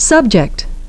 'subject <)) (noun) and sub’ject <)) (verb), 'object <)) (noun) and ob’ject <)) (verb).